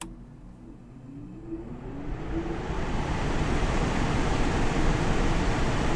空调多联机 " 仅空调启动风扇 - 声音 - 淘声网 - 免费音效素材资源|视频游戏配乐下载
我的窗式空调启动了，在风扇设置上。
用Yeti USB话筒在立体声设置下录制。麦克风放在离设备大约6英寸的地方，就在空气出来的顶部通风口的下面。一些非常低的频率的隆隆声被稍微衰减了。